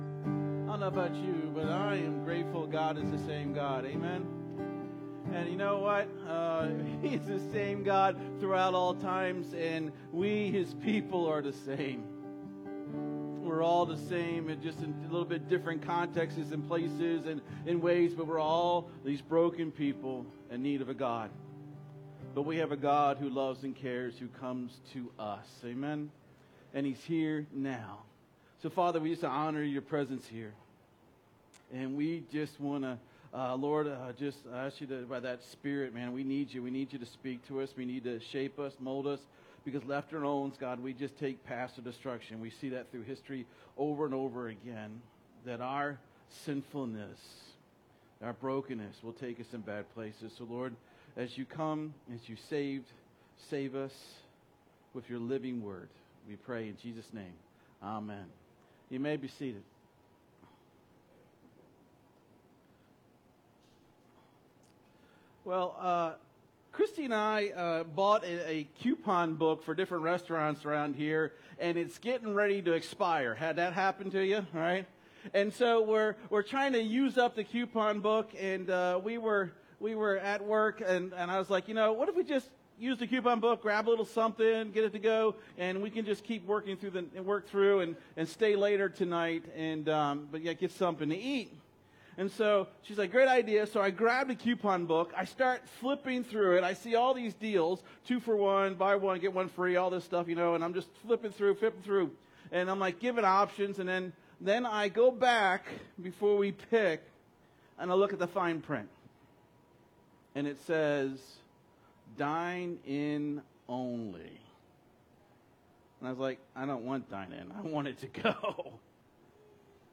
What's Jesus What's Jesus' Heart What's Jesus Guest Speaker September 15, 2024 Current Sermon What Jesus requires!